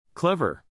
pretty /PRE-tty/,